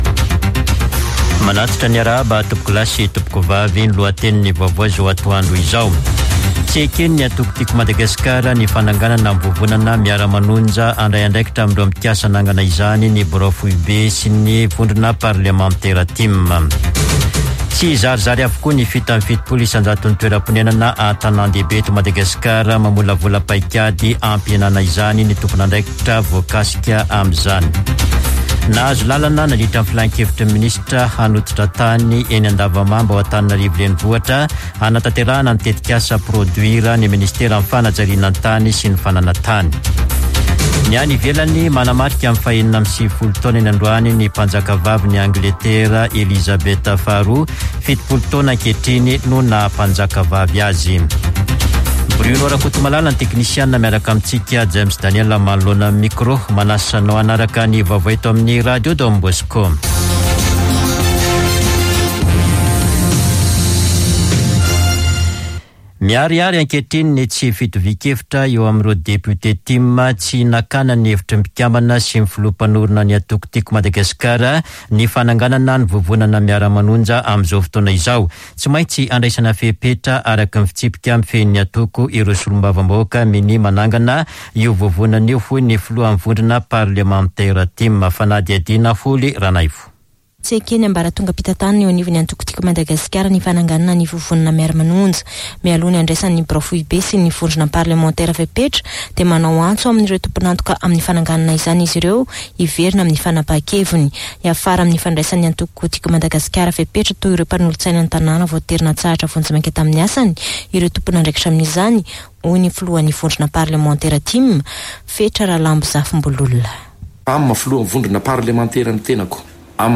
[Vaovao antoandro] Alakamisy 21 aprily 2022